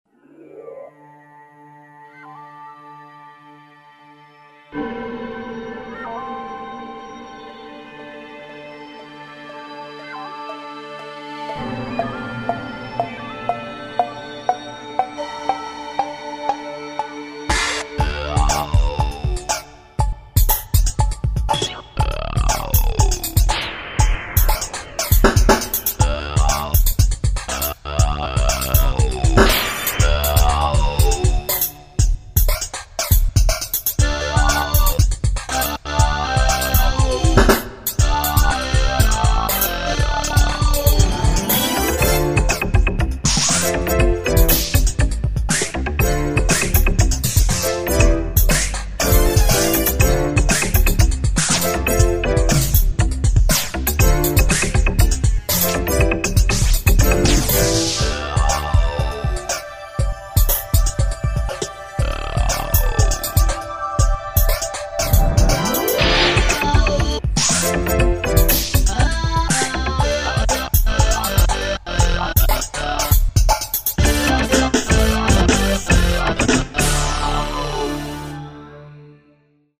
Z-plane Synthesizer (1993)
Rackmount synthesizer able to "morph" sounds creating motion textures and evolving pads, simply using the control wheel controller.
factory demo1